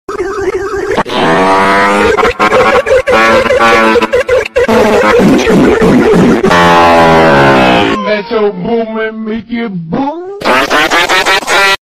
long brain fart
u3-long-brain-fart.mp3